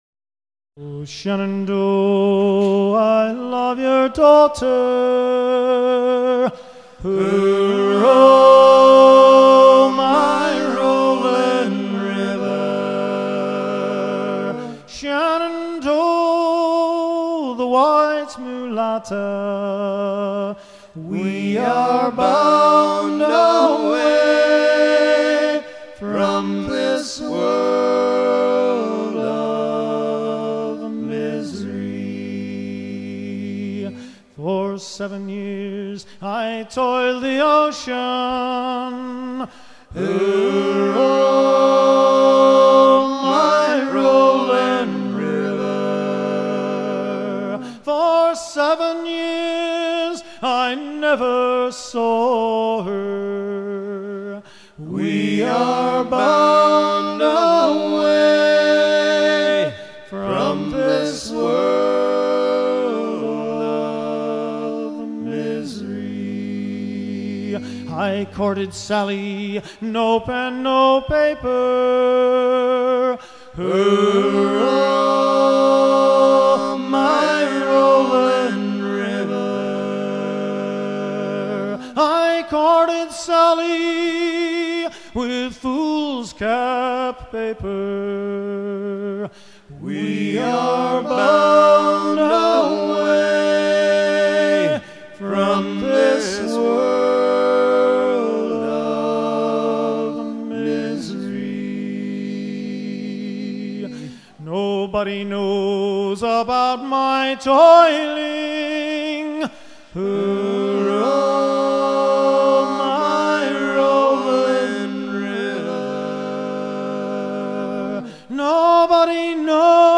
shanty à virer au guindeau ou au cabestan
Pièce musicale éditée